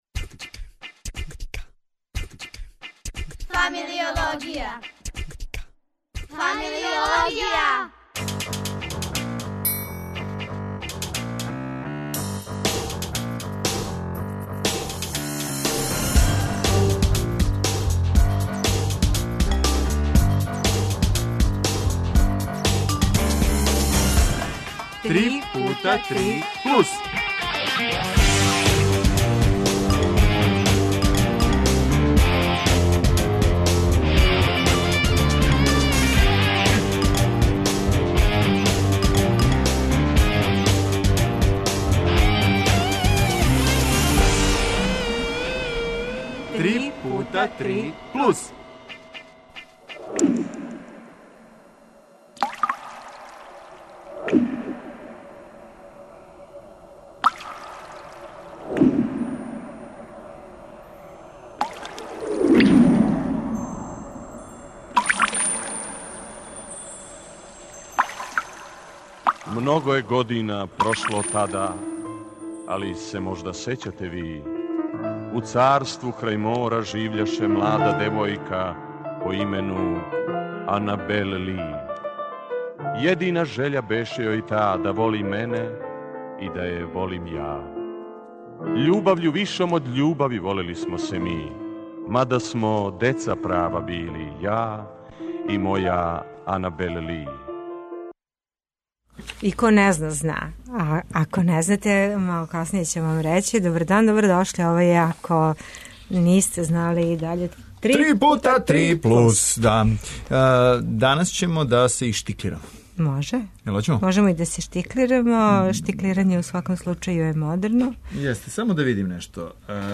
Гости су студенти Академије лепих уметости...